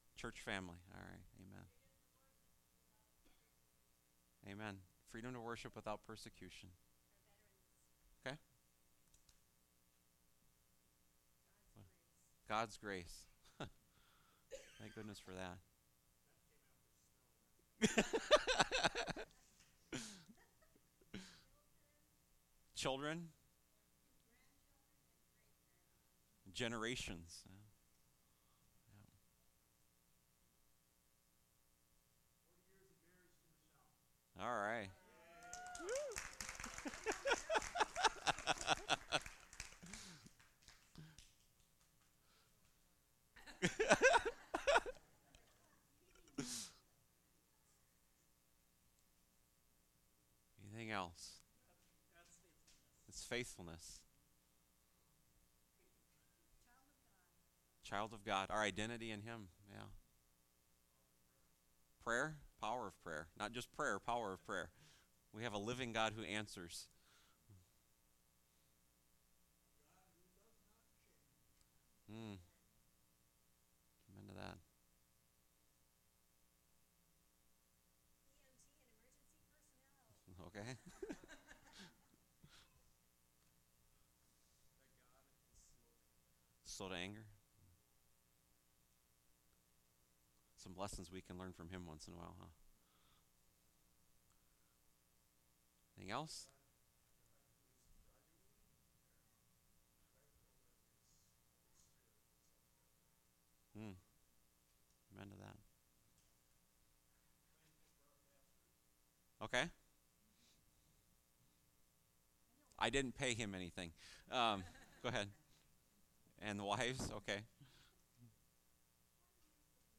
Thanksgiving Service